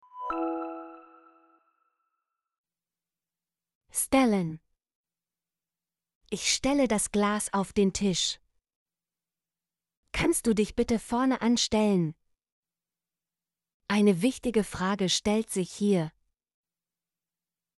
stellen - Example Sentences & Pronunciation, German Frequency List